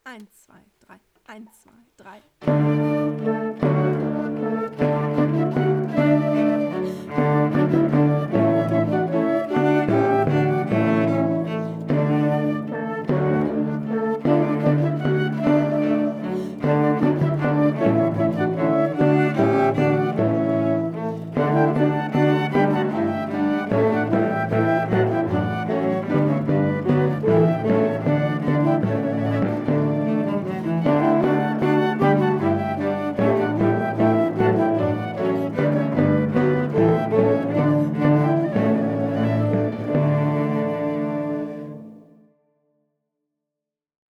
So ist das neueste Projekt eine Mehrspuraufnahme  von fünf Sätzen aus der Feuerwerksmusik von Händel, wo jede/r Schüler*in zu einem vorgefertigten Playback einzeln seine/ihre Stimme einspielt.
Playback 1), aber  schon nach der ersten Woche wurden neue Spuren hinzugefügt (